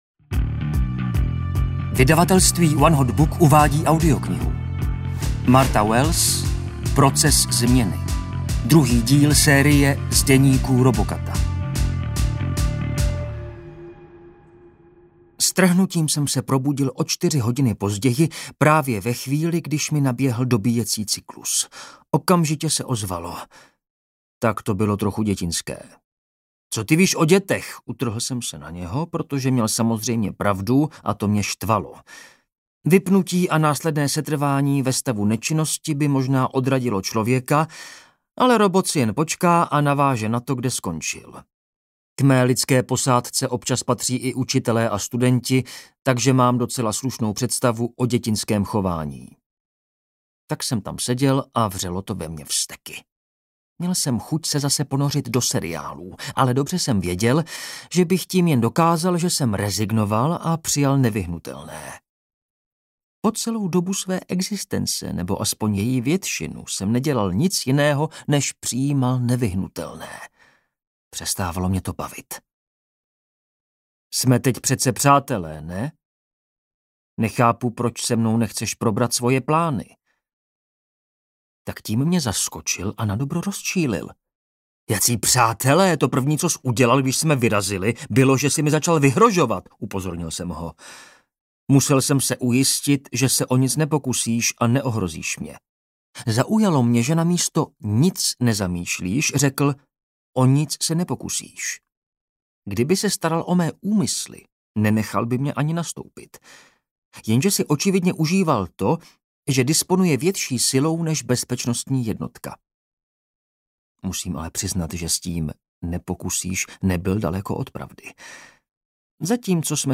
Ukázka z knihy
• InterpretDaniel Bambas